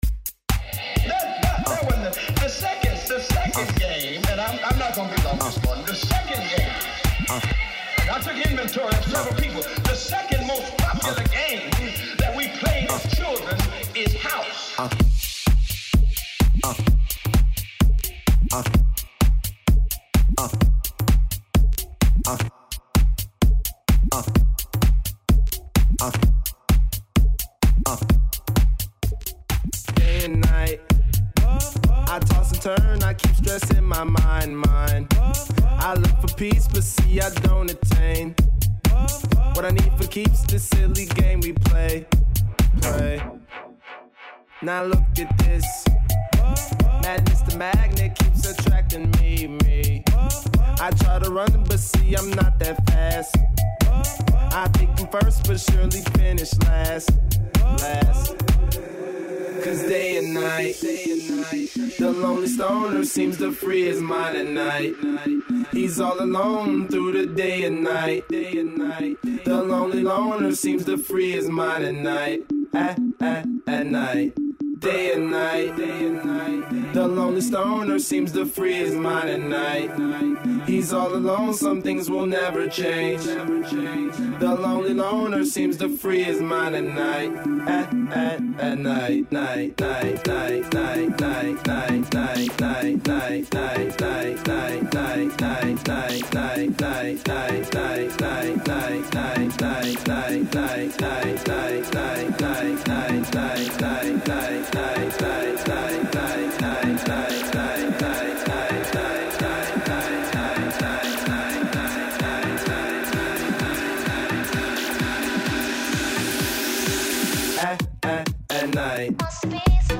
It is a heavy house mix ranging from 128-132 BPM.